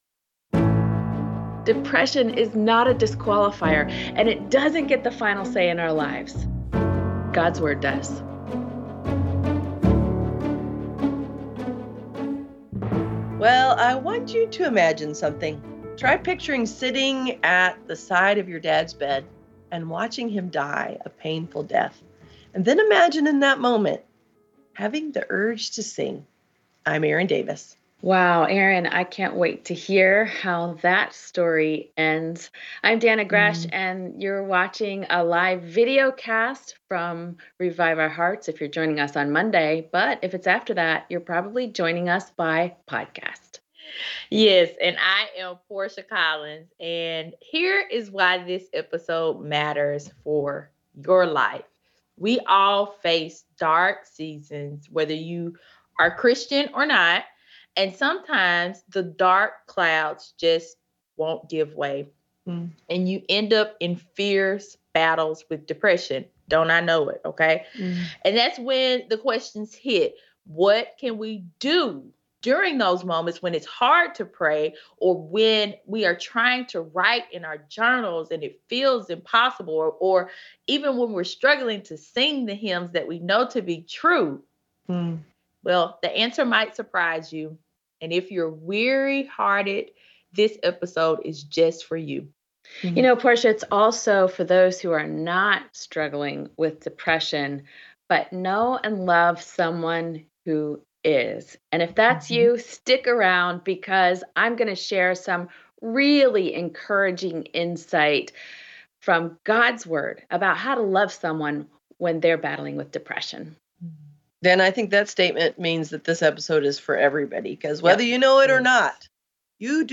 You're watching a live videocast from Revive Our Hearts , if you're joining us on Monday.